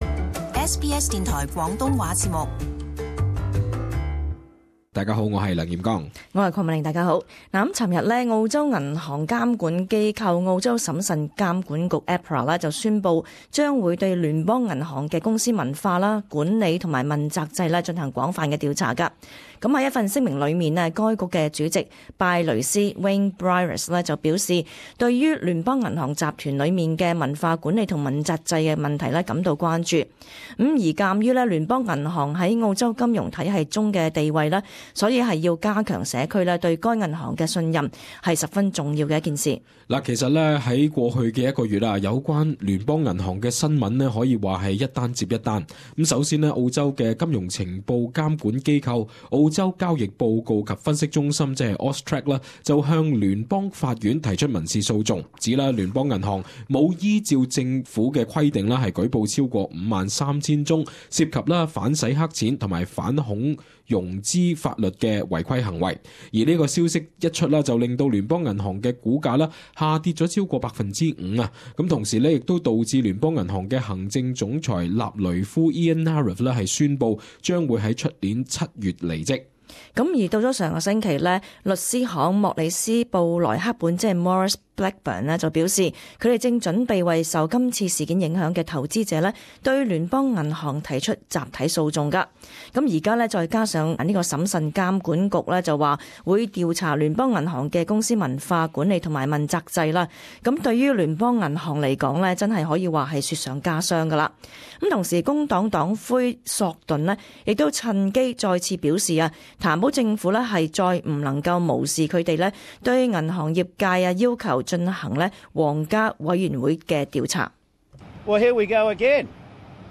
【时事报导】审慎监管局将调查联邦银行的运作